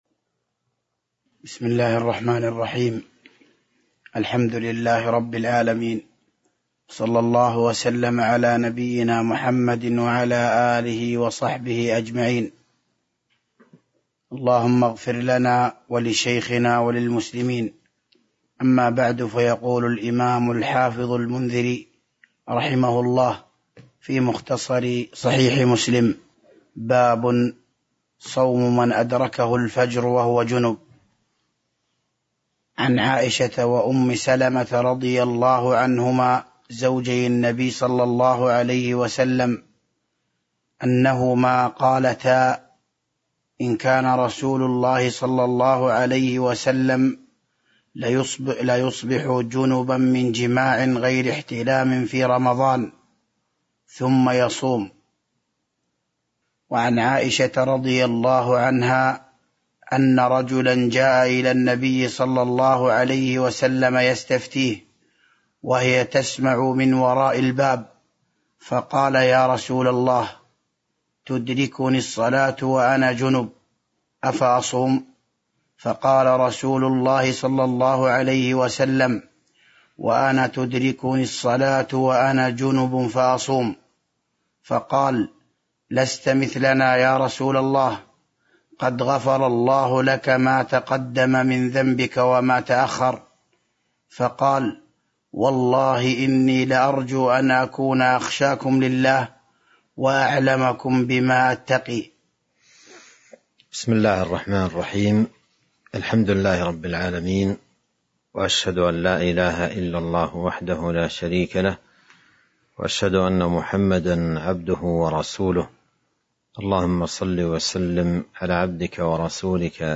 تاريخ النشر ٨ شعبان ١٤٤٢ هـ المكان: المسجد النبوي الشيخ